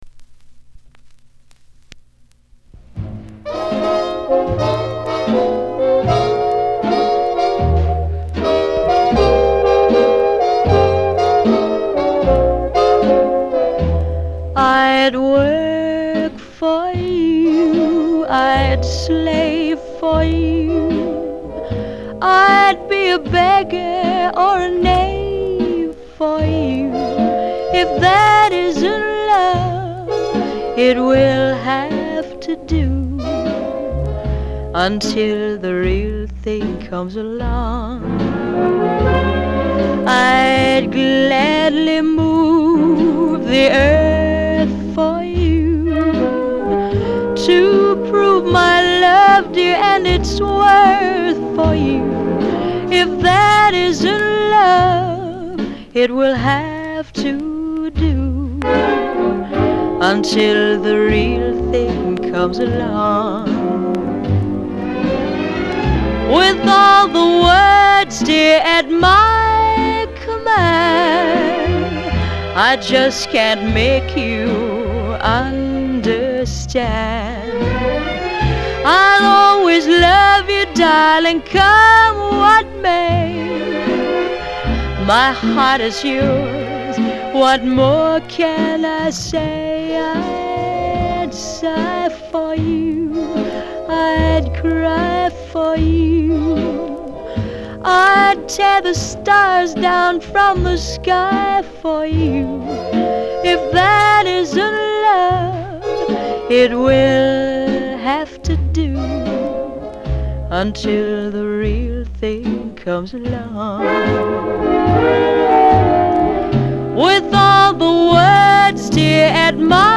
형식:레코드판, LP, Album, Mono
장르:Pop 1955.